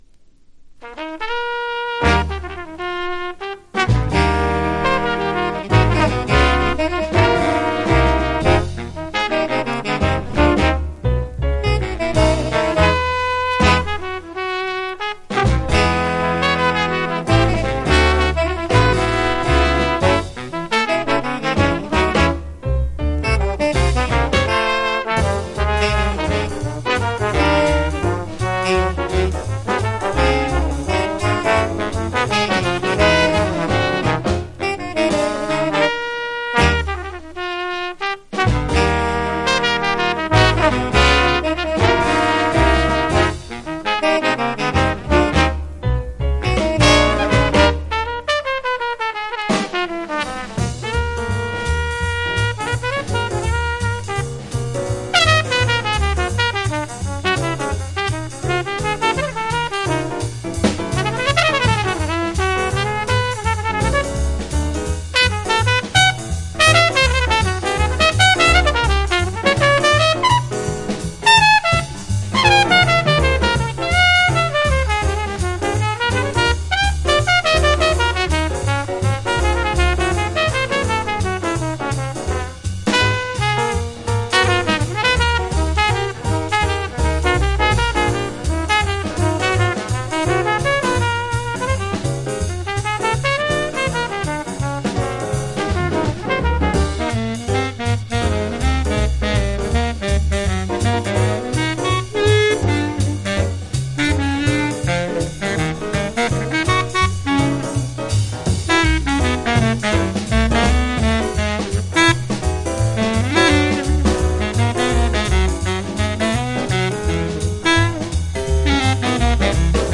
（MONO針で聴…